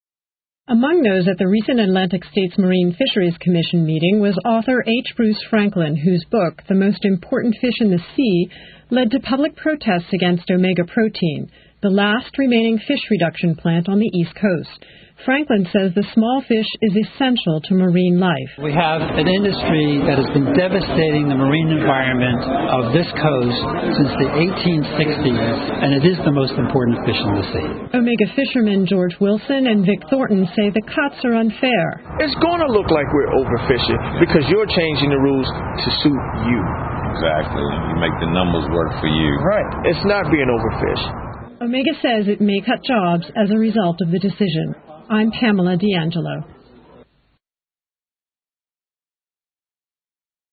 Fish Fight | Virginia Public Radio